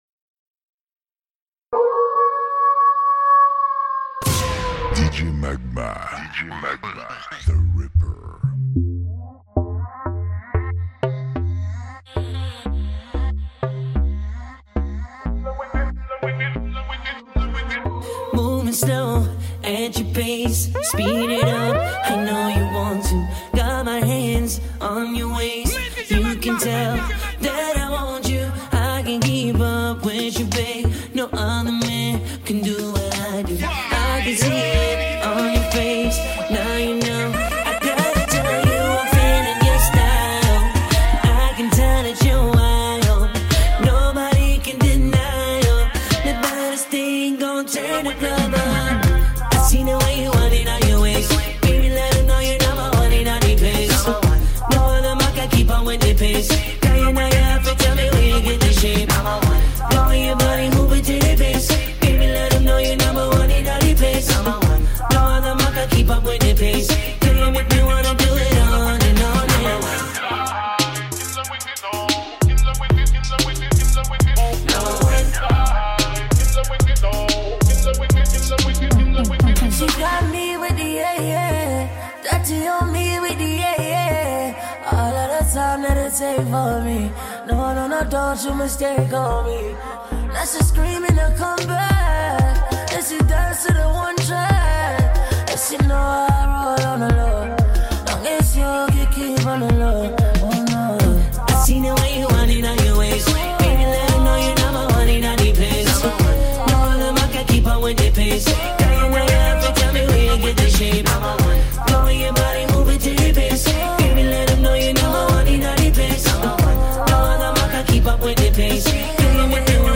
Genre : MIXES